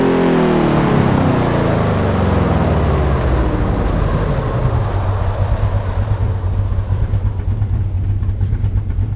Index of /server/sound/vehicles/lwcars/dodge_daytona
slowdown_highspeed.wav